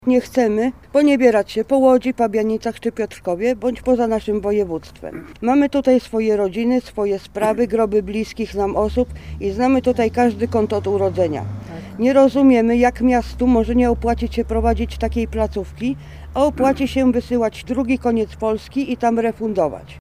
Wiceprezydent miasta Łukasz Politański mówi, że wszystkim zostaną zapewnione godne warunki życia. Tłumaczy, że obiekt, w którym znajduje się schronisko jest do wyburzenia.